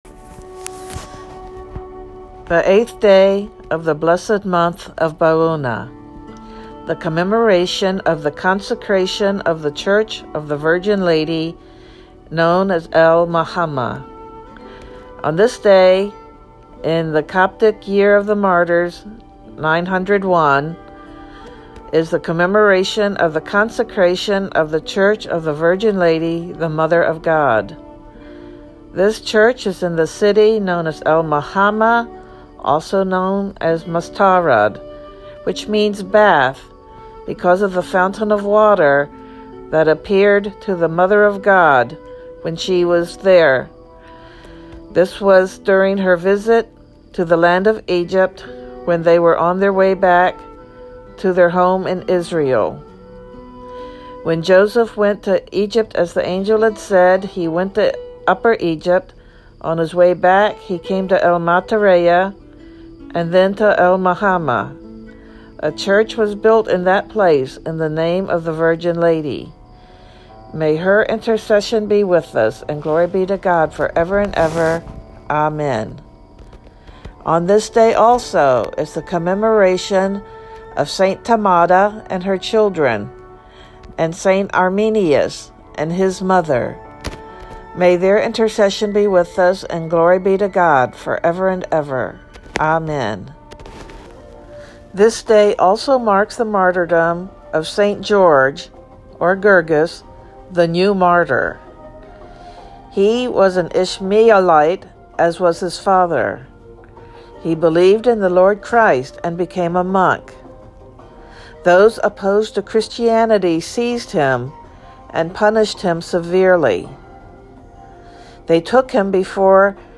Synaxarium readings for the 8th day of the month of Baounah